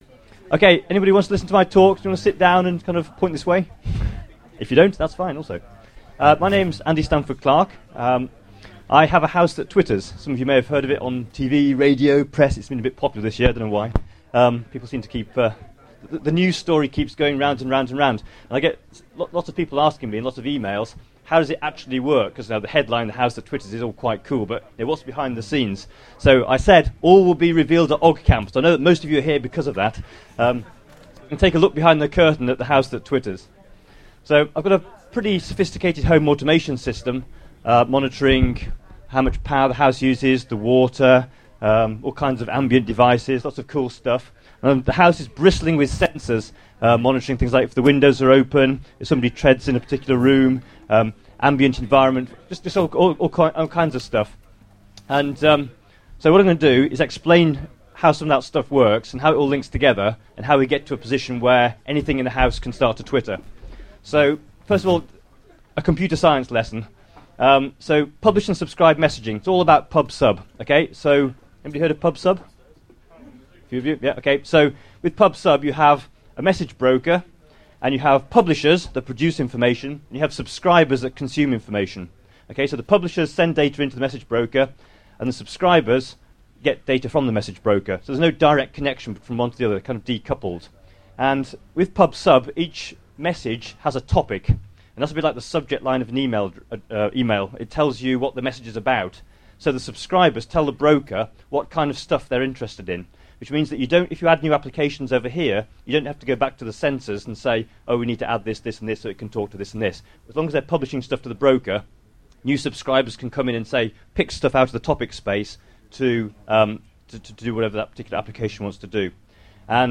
the audio from the talk at oggcamp